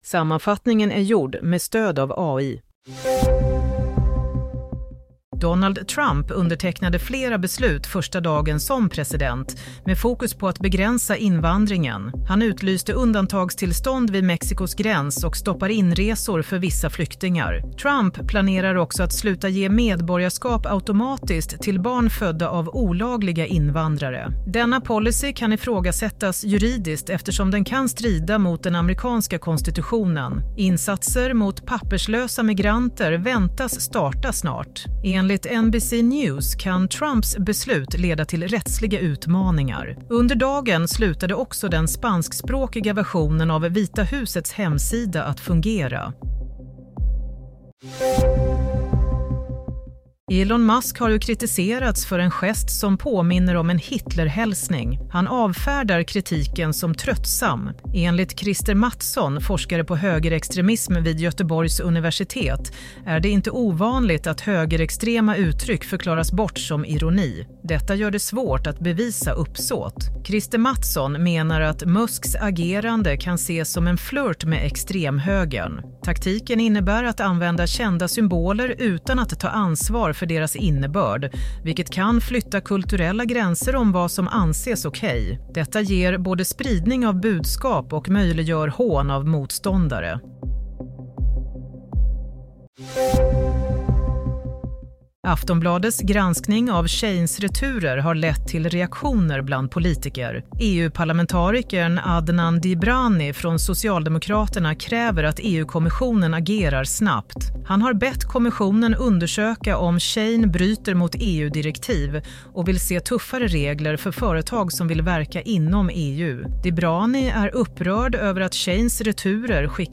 Nyhetssammanfattning – 21 januari 22:00
Sammanfattningen av följande nyheter är gjord med stöd av AI.